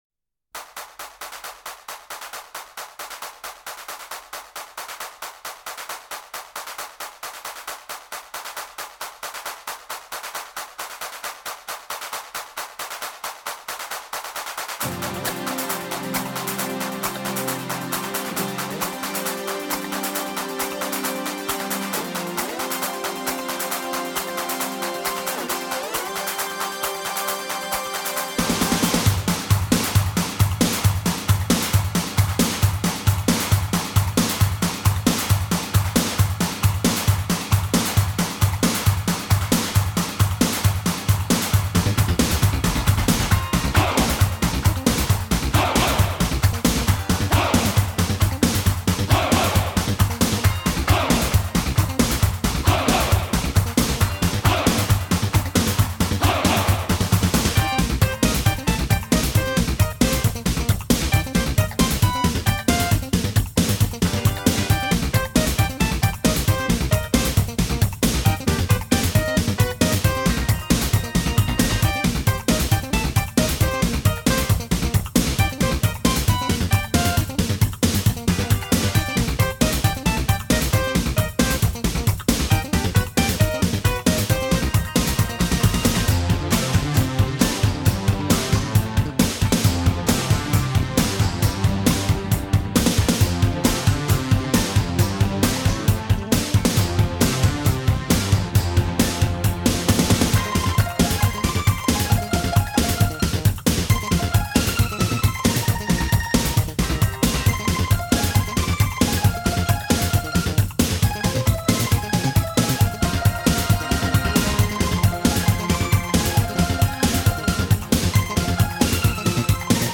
从包装设计到里面的音乐都透着一股强烈的民族特色
其余七首是纯乐曲。
整张专辑制作精良，乐曲节奏明快，精彩纷呈！
音场的空气感、乐器的细致度和震撼性的低音都靓到令人吃惊